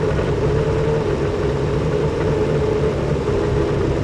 f1_01_idle.wav